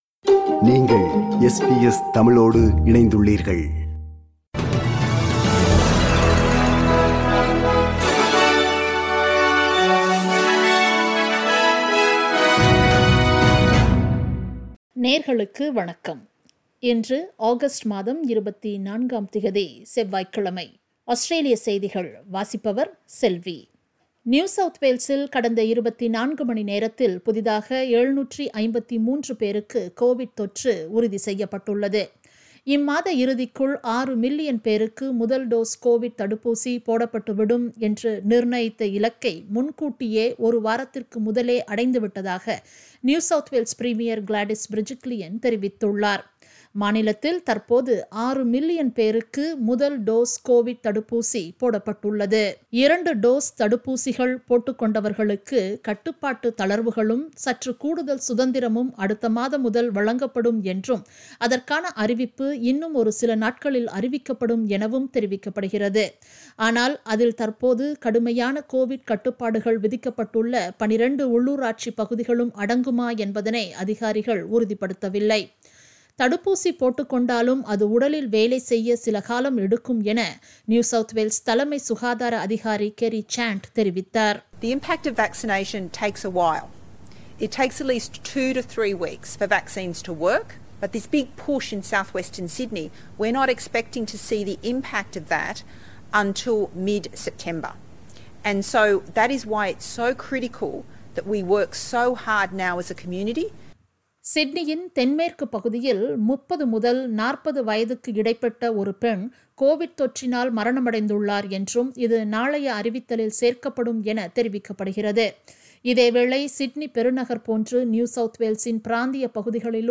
Australian news bulletin for Tuesday 24 August 2021.